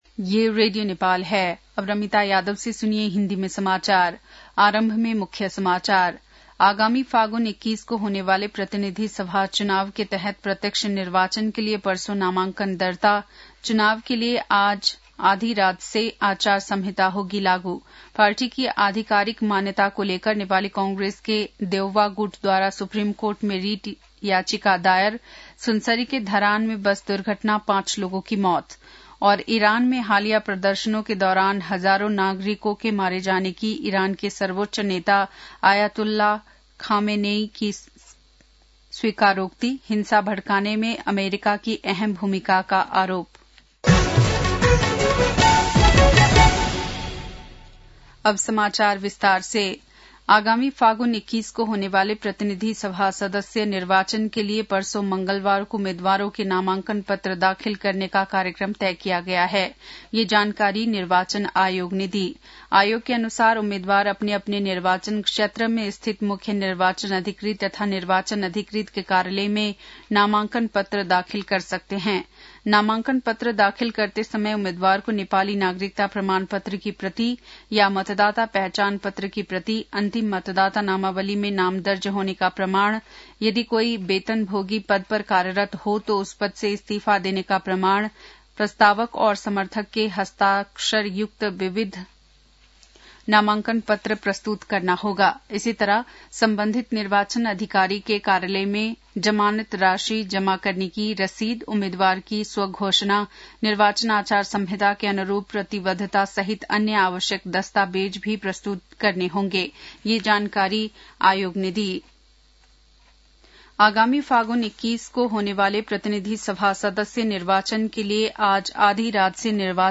बेलुकी १० बजेको हिन्दी समाचार : ४ माघ , २०८२